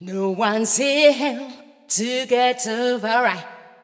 House / Voice